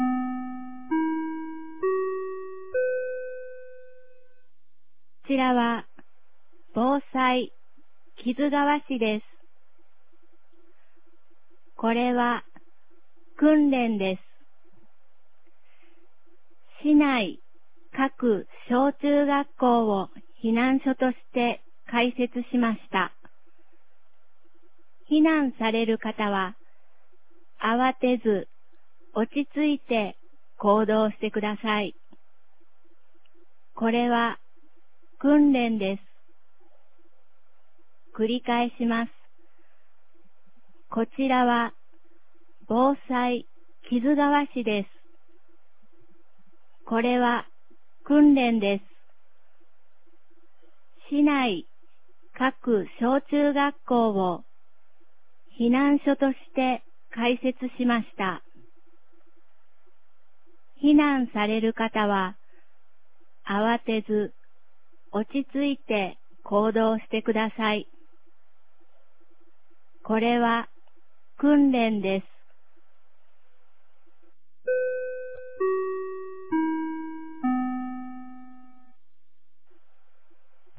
2019年10月20日 09時31分に、木津川市より木津地区、加茂地区、山城地区へ放送がありました。
放送音声